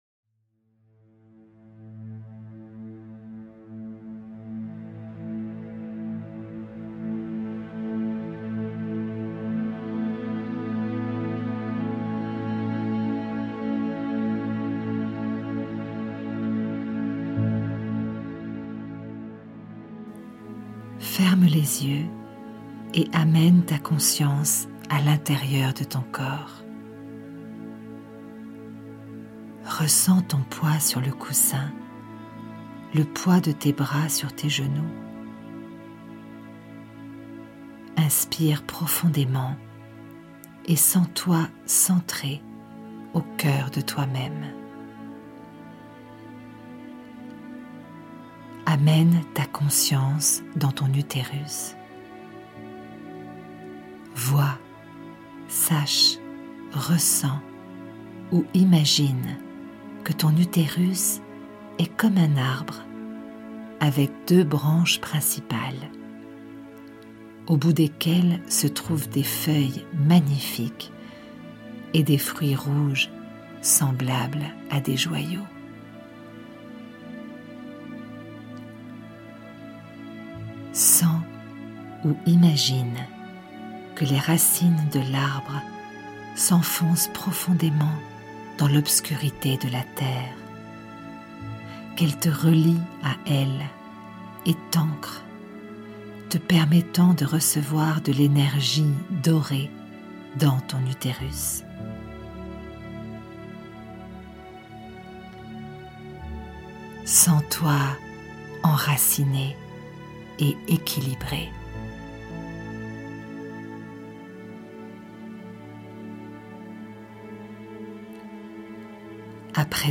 Méditation de l'Arbre Utérus
Meditation-Arbre-Uterus-WB-longue.mp3